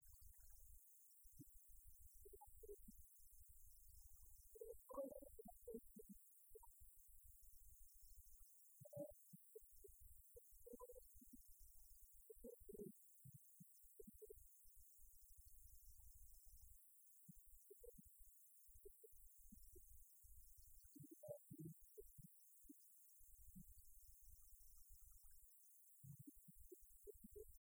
Genre strophique
Concert de la chorale des retraités
Pièce musicale inédite